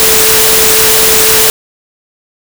A continuación se puede acceder a cinco audiciones que harán comprender mejor la audición binaural humana, y para las cuales se hace necesario la utilización de auriculares.
Audición 1: Tono de referencia (onda sinusoidal) de 440 Hz. para futuras comparaciones.
Audición 4: Tono de referencia más ruido en el auricular izquierdo y en el derecho el mismo tono (en fase) más ruido.